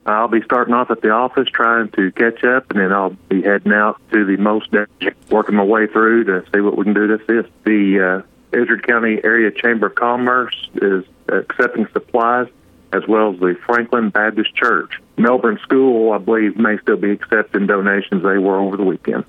Izard County Sheriff Charley Melton spoke with KTLO, Classic Hits and the Boot News Monday morning to give an update on conditions and coming relief to areas hit by the storms in Izard County.